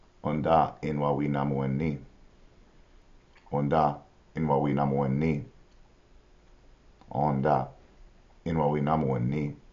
Ô-da  n-wa-wi-na-wô-wi